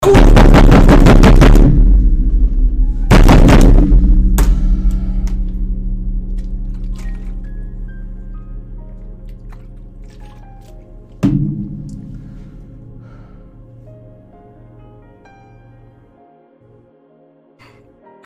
failsound.mp3